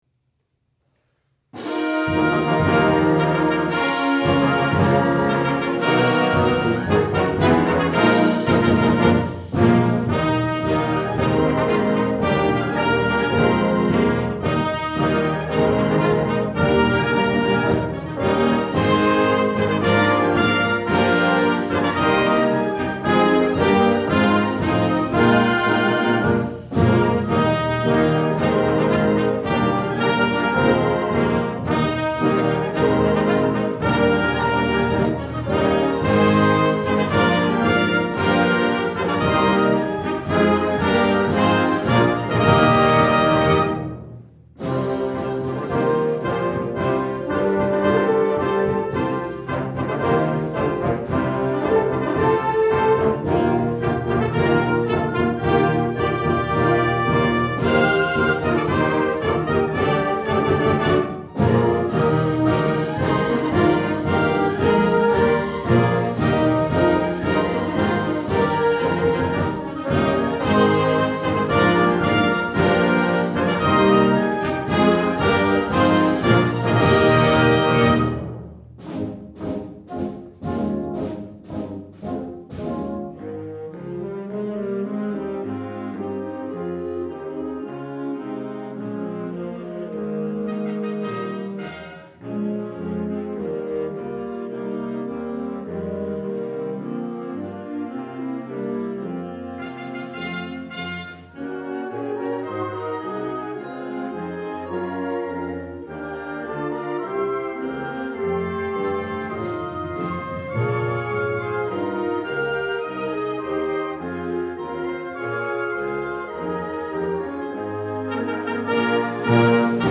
Style: Concert March
Instrumentation: Standard Concert Band